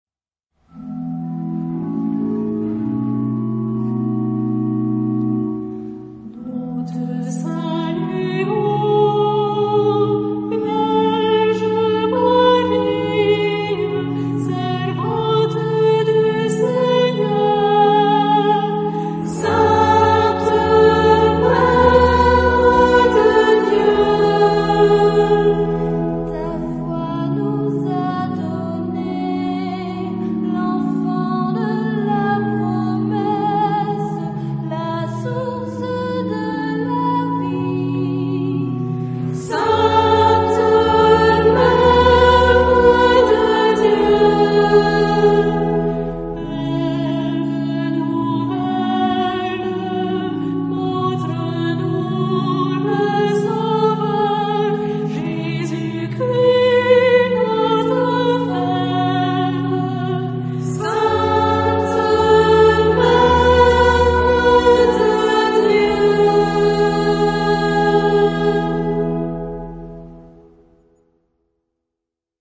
Antienne mariale
Genre-Style-Form: Prayer
Mood of the piece: trusting
Type of Choir:  (1 unison voices )
Instruments: Organ (1)
Tonality: F major
Liturgical Use: Marian antiphon